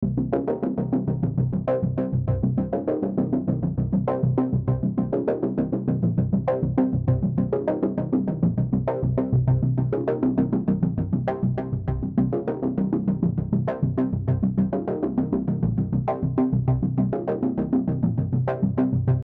Я прикрепил пример, играет синтезатор стандартную партию. На мой взгляд, вылетает кака на самой высокой ноте - около 500 герц, хотя пиков нет. Во второй части примера подрезана узким колоколом эта частота на 12dB.